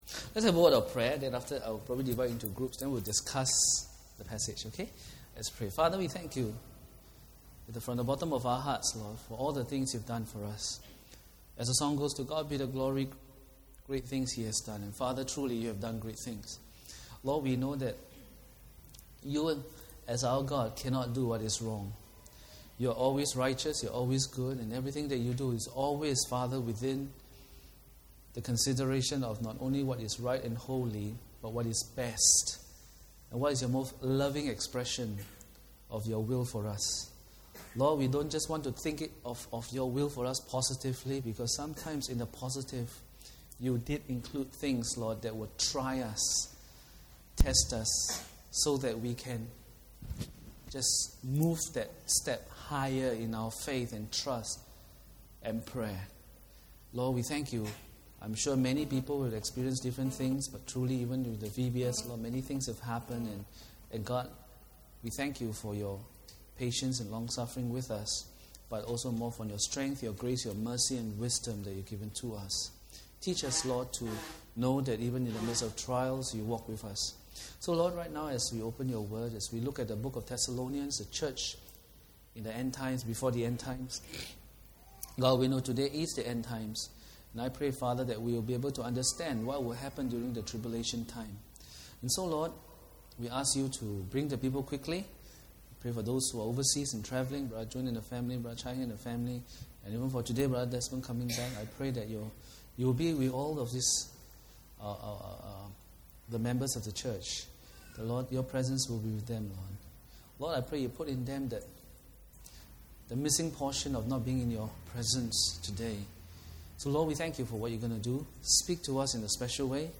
The Terrible Time of the Tribulation Preached by